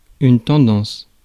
Ääntäminen
France: IPA: [tɑ̃.dɑ̃s]